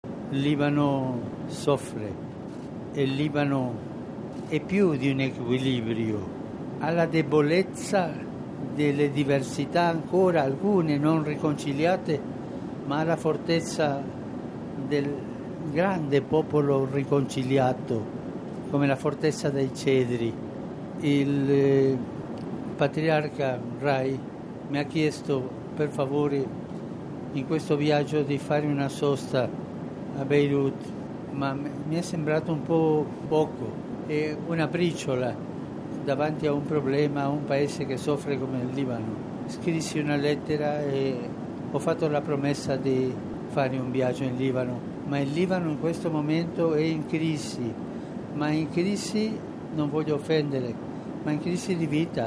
Nella conferenza stampa l’8 marzo del 2021 durante il volo di ritorno, il Pontefice ha pronunciato queste parole rispondendo ad una domanda su una possibile e imminente visita: